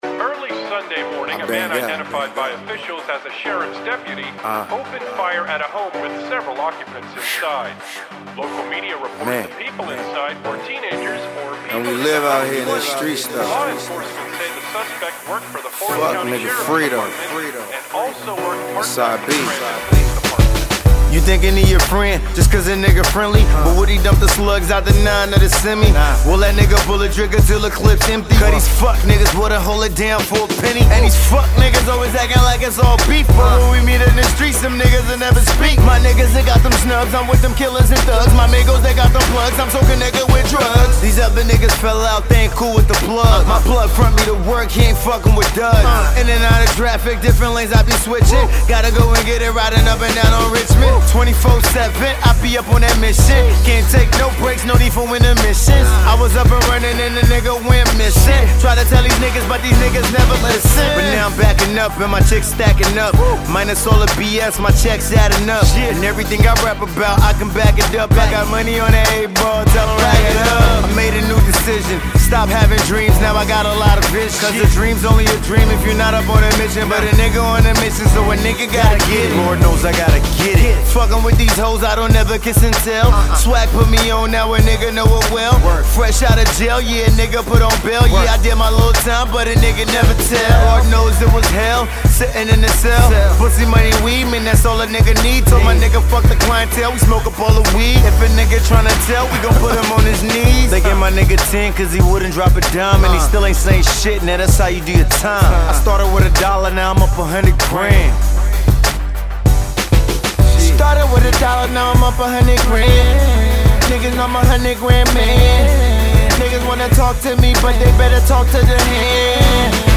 Hip-Hop